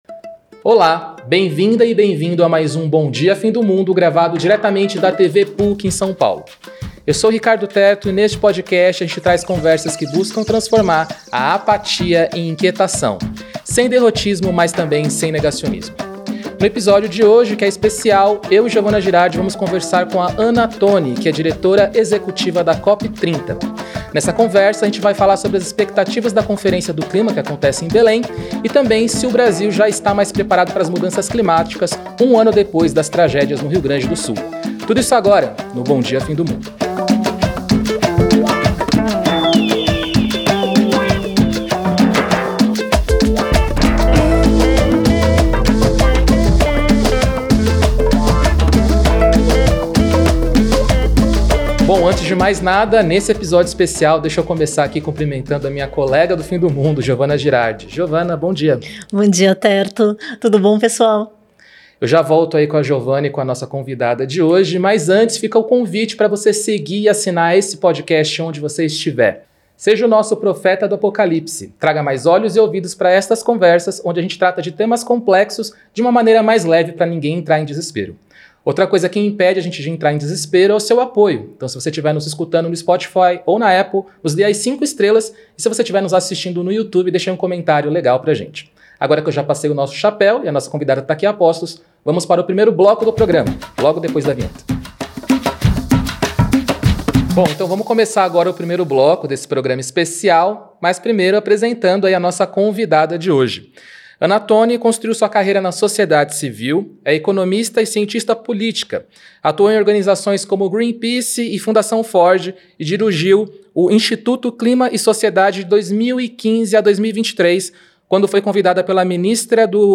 Entrevista com Ana Toni: o que esperar da COP30 no Brasil